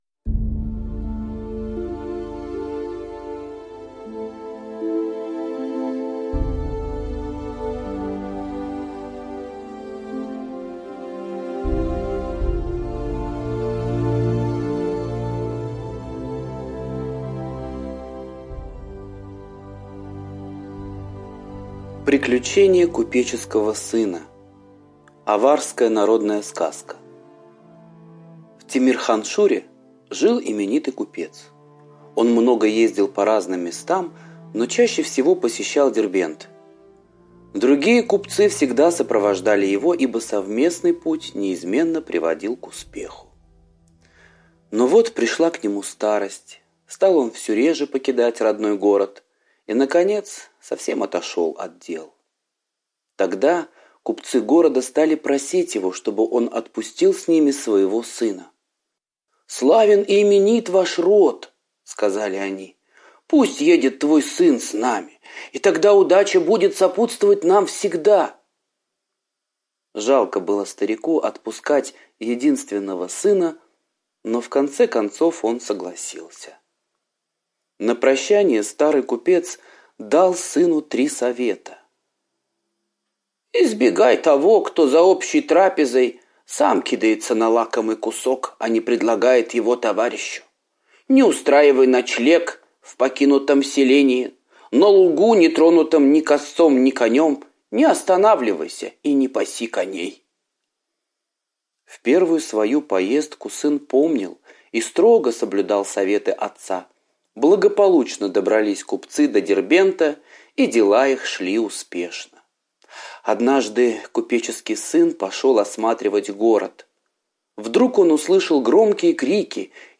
Приключение купеческого сына - восточная аудиосказка - слушать онлайн